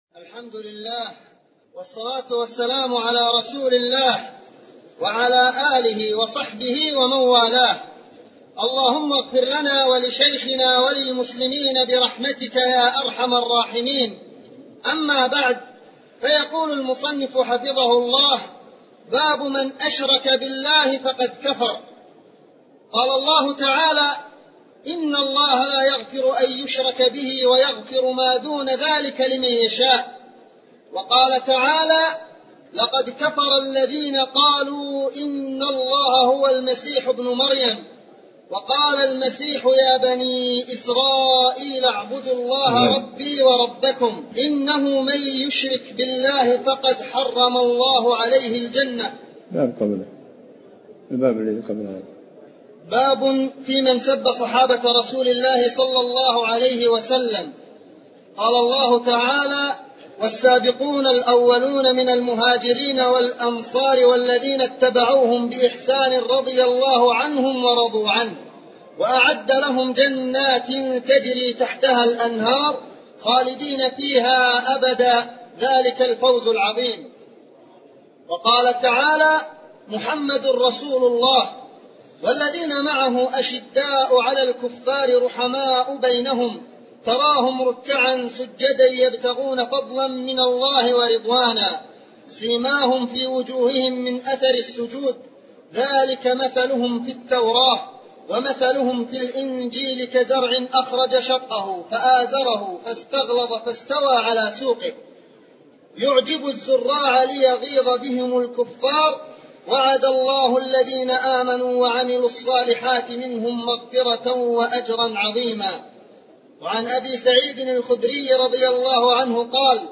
تفاصيل المادة عنوان المادة الدرس (49) شرح المنهج الصحيح تاريخ التحميل الأحد 15 يناير 2023 مـ حجم المادة 31.61 ميجا بايت عدد الزيارات 331 زيارة عدد مرات الحفظ 113 مرة إستماع المادة حفظ المادة اضف تعليقك أرسل لصديق